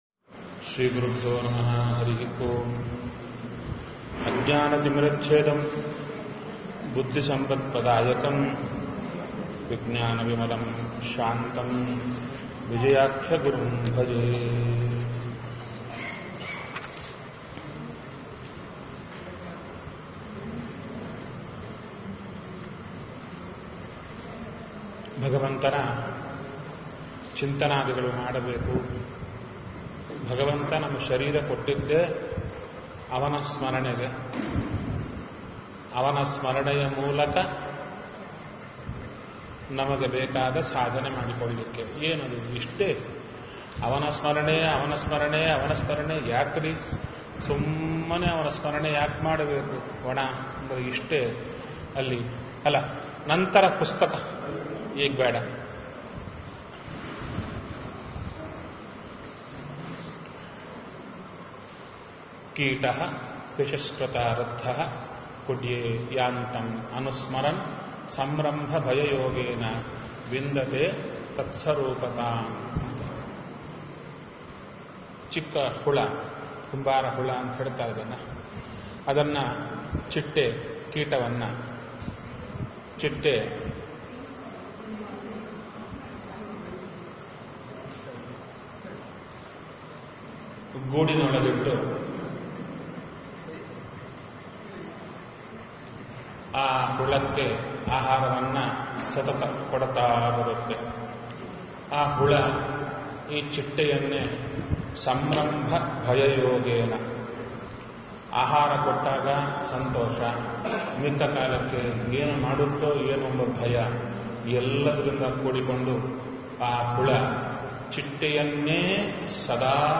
Spiritual discourses, audio messages, events, and downloadable resources from Kurnool Achars Chintana.